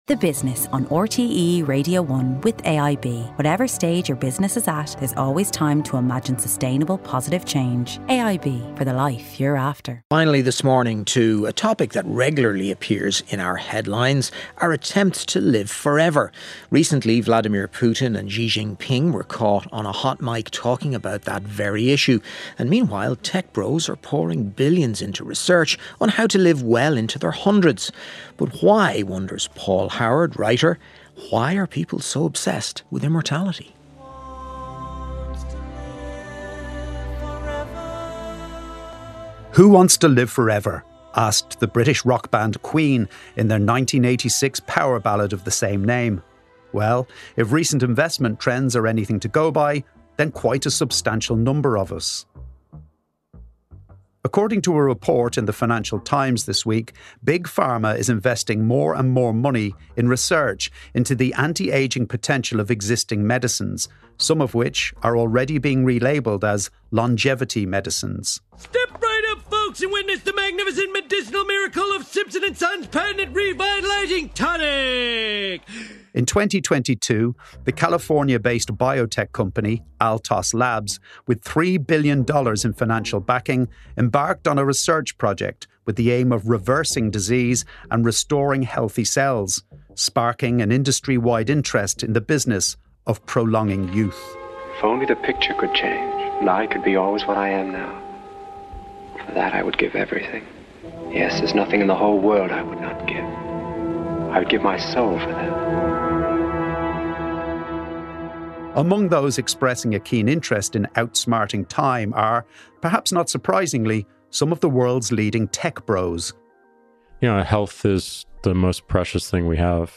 With billons being poured into anti-aging research and Vladimir Putin and Xi Jinping recently caught discussing the possibility of living well into their hundreds, writer Paul Howard shares his thoughts on why people are so obsessed with immorality.